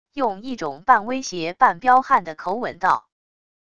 用一种半威胁半彪悍的口吻道wav音频